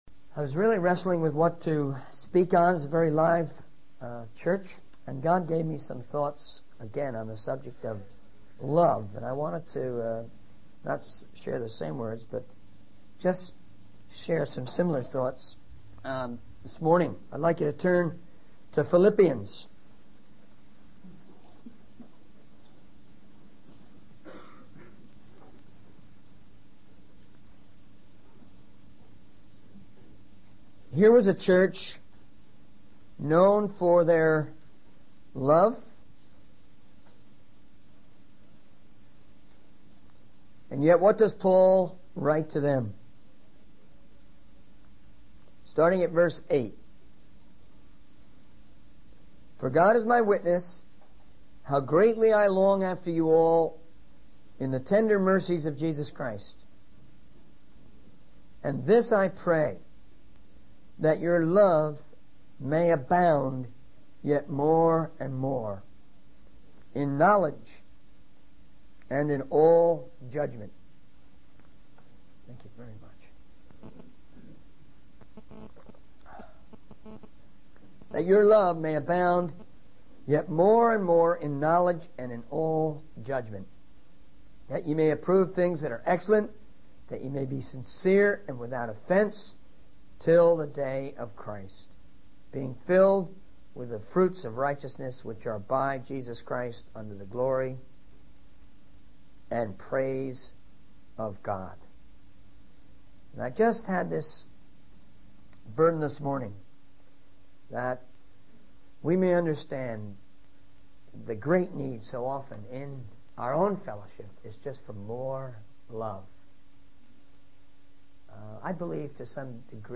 In this sermon, the speaker emphasizes the importance of love in the lives of Christians.